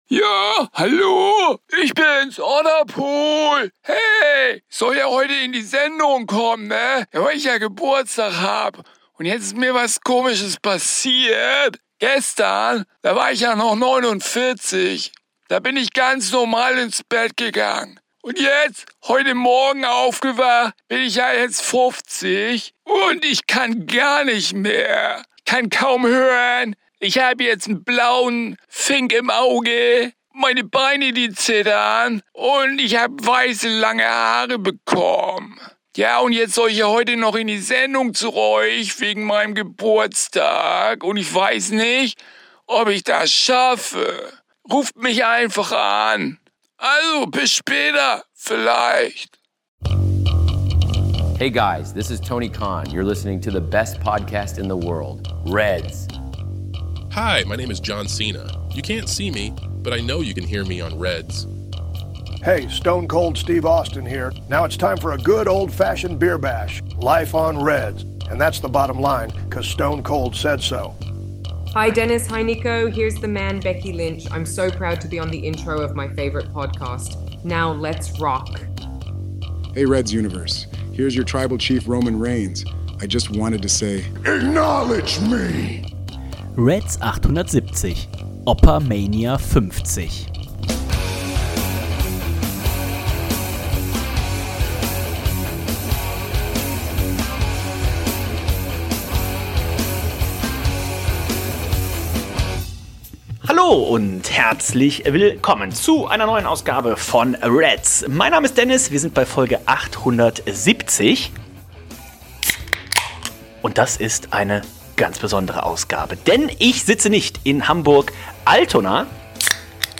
Danach widmen wir uns dem Thema AEW , schauen auf AEW Revolution , AEW Dynamite und natürlich dem Vorausblick auf die nächsten AEW PPVs bis hin zu All In in London am 30.08.26. Und dann wird’s wild : Wir schnappen uns unser Mikrofon